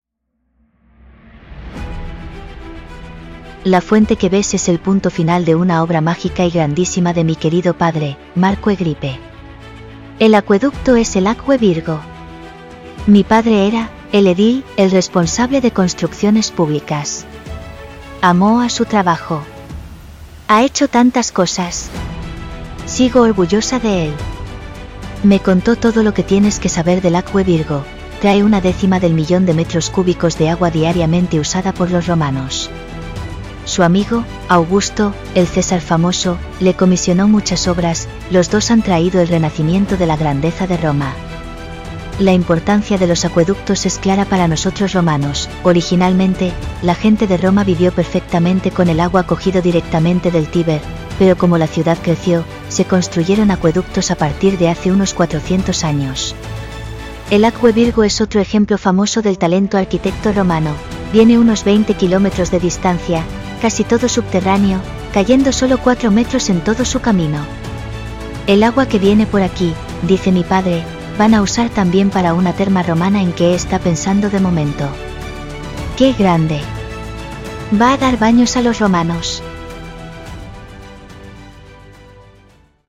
La hija de Marco Agrippa explica el rol del acueducto Acqua Virgo